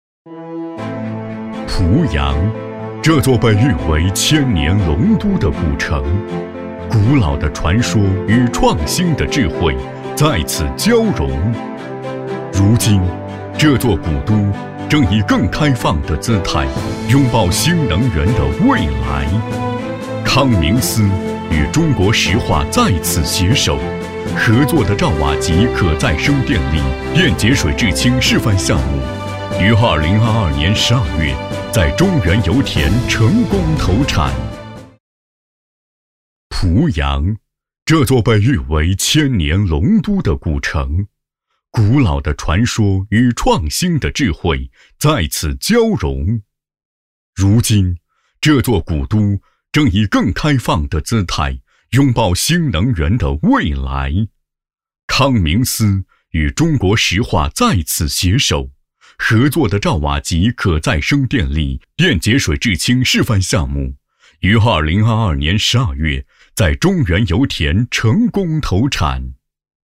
纪录片专题大气温暖配音 大学专题配音
国语配音
男382-城市宣传-濮阳.mp3